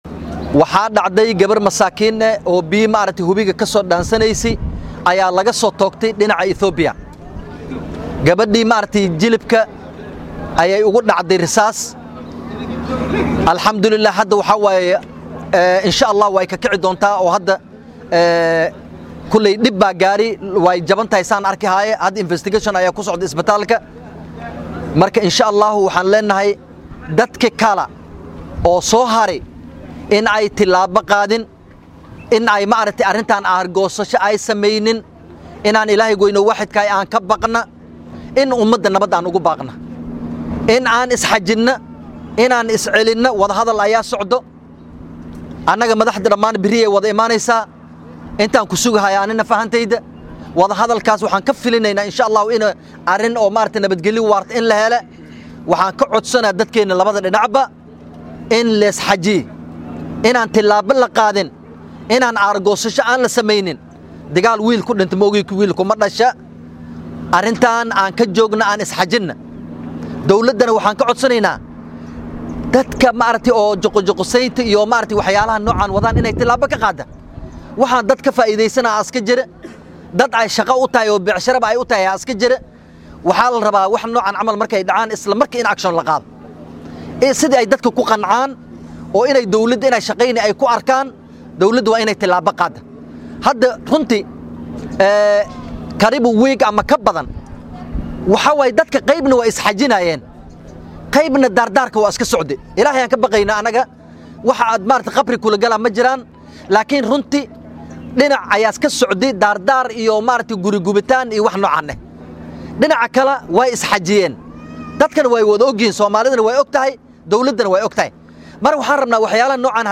DHAGEYSO:Xildhibaanka bariga Mandera oo ka hadlay dhacdo rasaas ka timid dhanka Itoobiya ay ku dhawaacantay hooyo